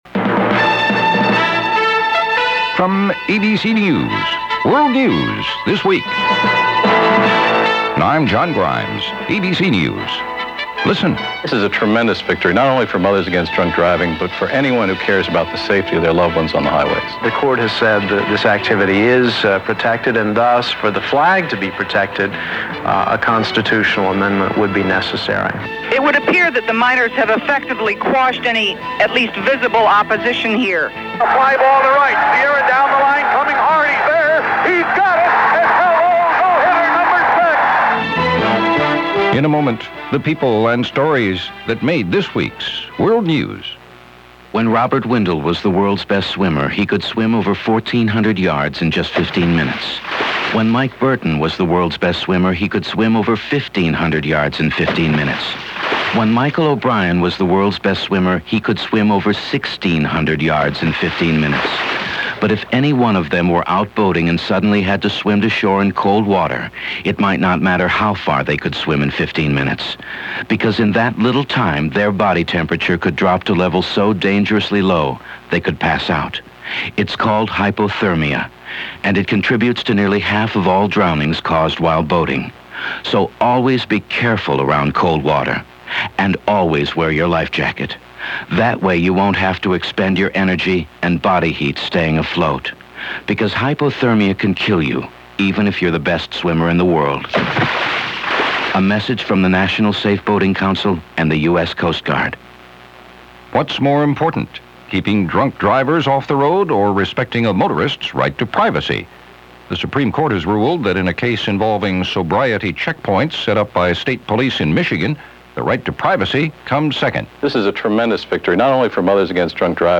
Click on the link here for Audio Player – ABC World News This Week – June 17, 1990